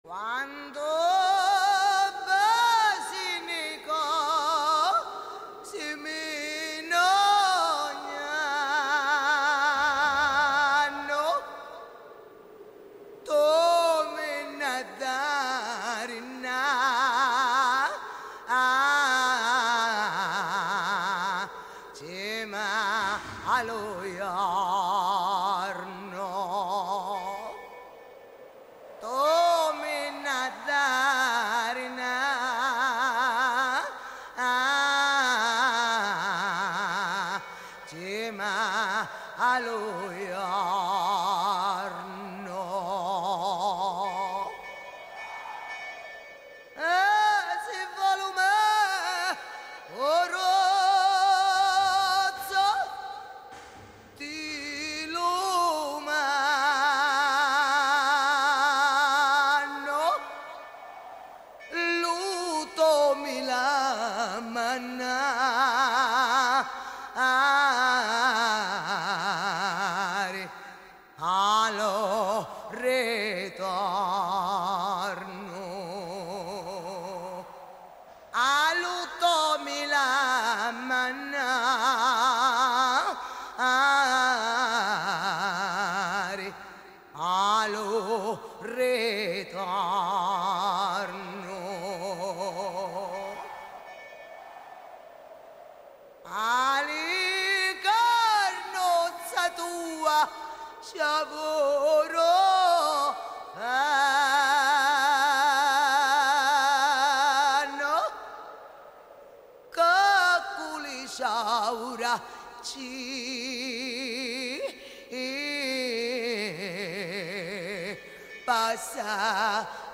Intervista a Carmen Consoli (1./2)